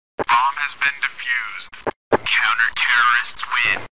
bomb-has-been-defused-counter-terrorists-win-cs_go-sound-effect.mp3